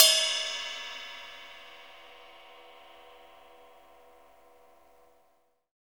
Index of /90_sSampleCDs/Roland - Rhythm Section/CYM_Rides 1/CYM_Ride menu
CYM ROCK 0MR.wav